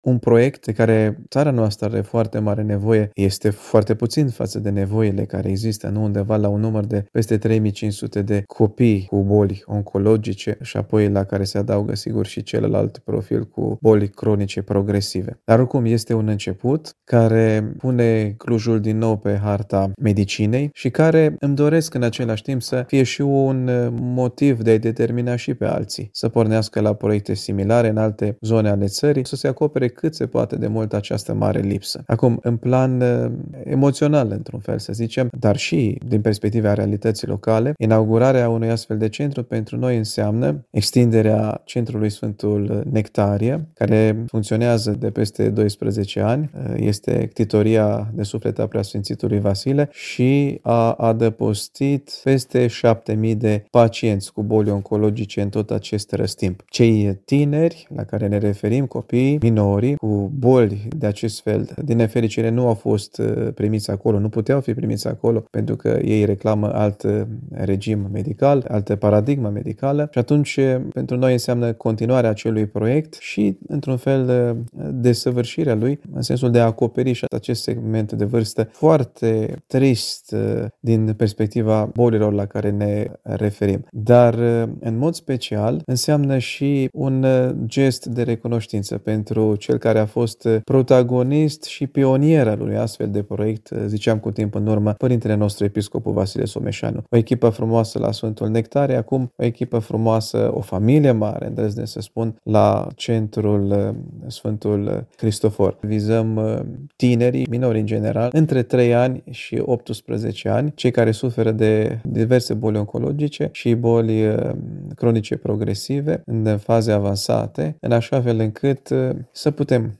Proiectul a fost finalizat cu succes și a adunat mulți oameni de bine care au contribuit semnificativ, într-un timp scurt, în mai puțin de doi ani, am aflat la o poveste tihnită cu Preasfințitul Părinte Benedict, Episcopul Sălajului și Președinte al Asociației “Sfântul Nectarie” din Cluj: